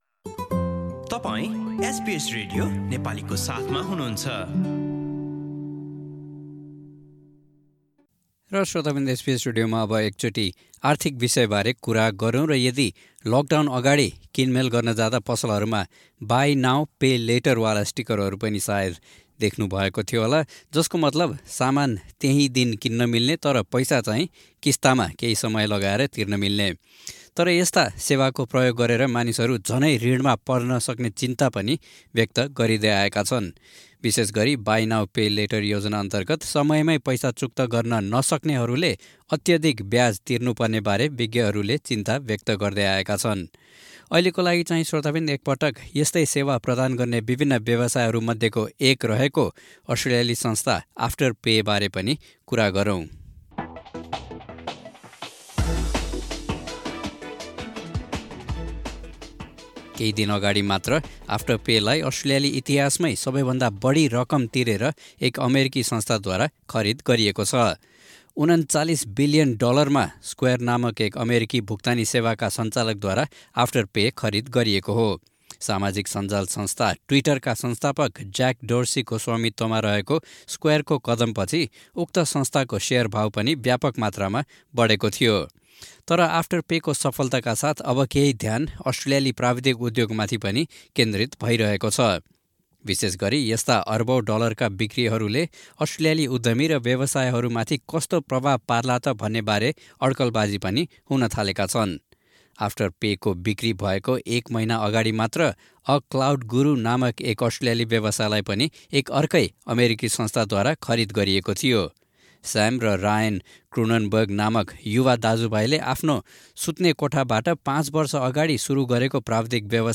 रिपोर्ट सुन्नुहोस:null हाम्रा थप अडियो प्रस्तुतिहरू पोडकास्टका रूपमा यहाँबाट नि:शुल्क डाउनलोड गर्न सक्नुहुन्छ।